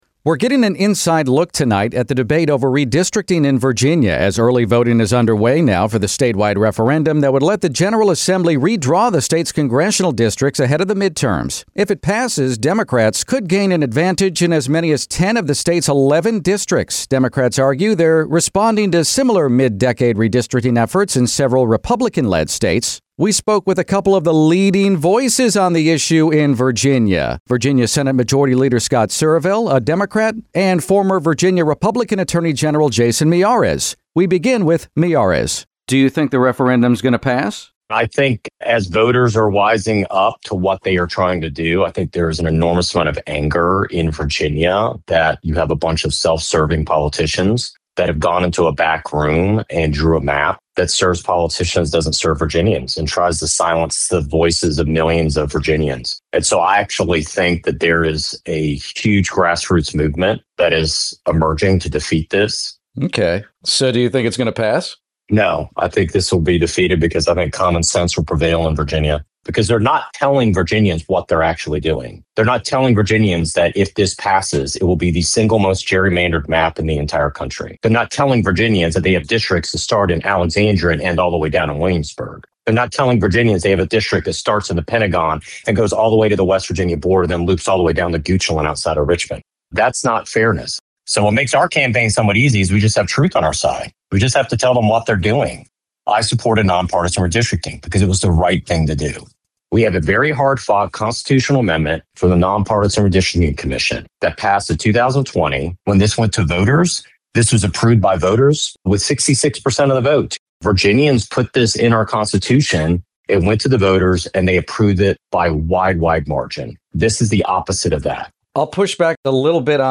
speaks with Virginia's Scott Surovell and Jason Miyares on the congressional redistricting referendum in April